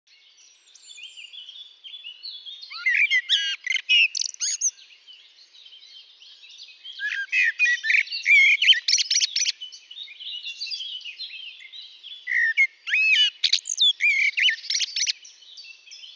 mn   le Merle noir
Il chante dès janvier, le plus souvent le matin et le soir. Comme d'autres cousins turdidés, il est inventif et s'écoute sans se lasser !
Merle noir MN1.mp3